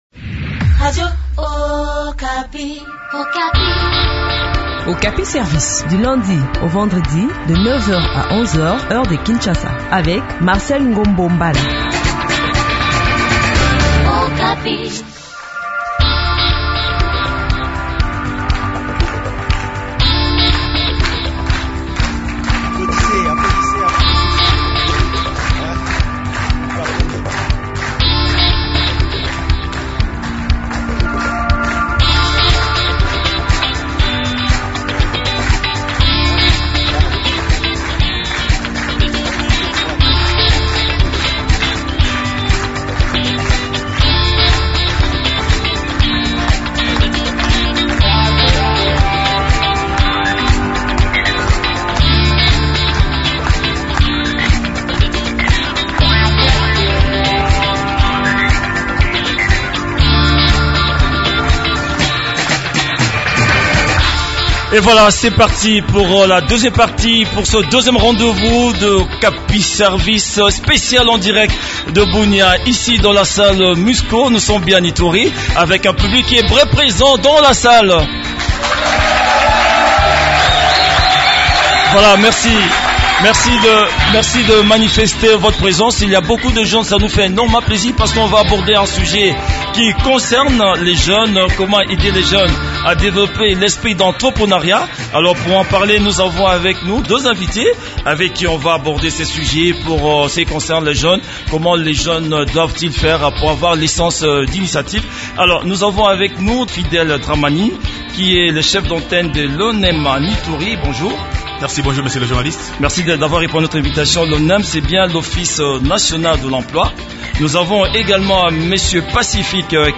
Cette émission est spécialement produite à partir de Bunia (Ituri) en marge des festivités de soixante-dix ans d’existence de l’Organisation des Nations unies (ONU).